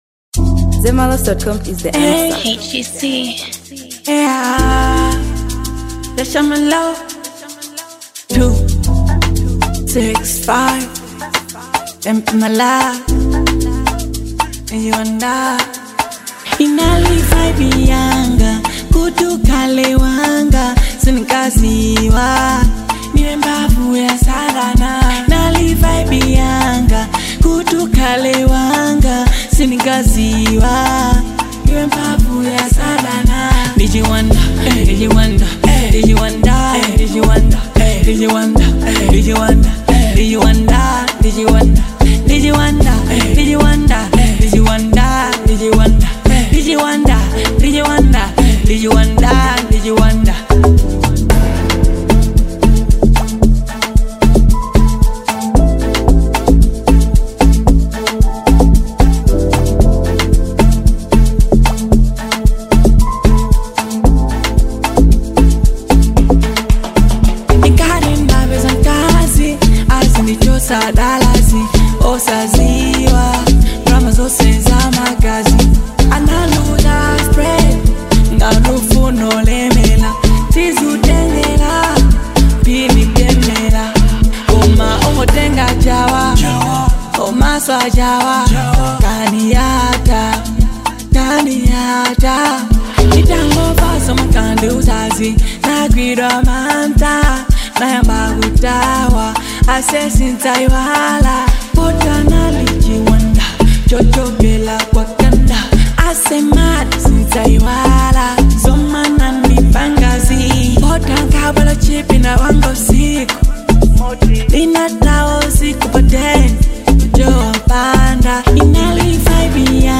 Genre: Amapiano.